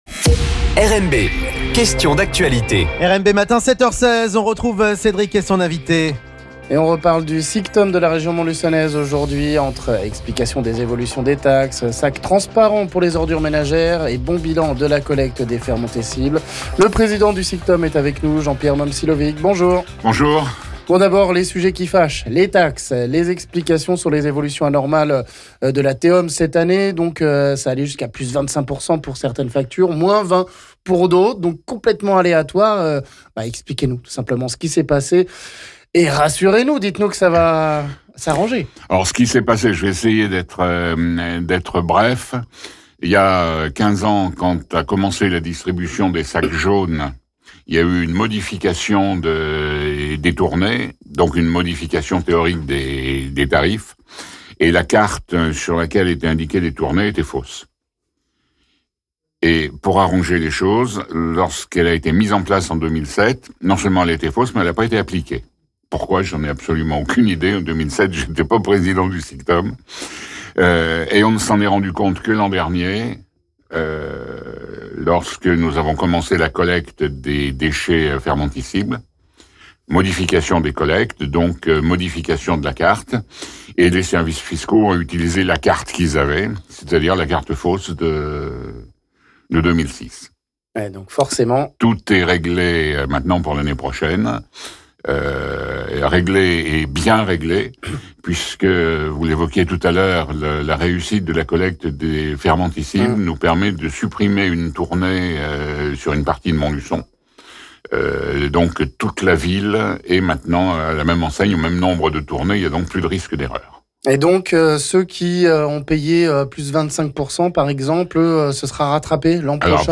Les taxes de l'Etat qui restent élevées malgré les efforts niveau tri, la mise en place l'an prochain de sacs transparents pour les ordures ménagères afin d'inciter à mieux trier...on parle de ces sujets avec le président du SICTOM de la région montluçonnaise Jean-Pierre Momcilovic...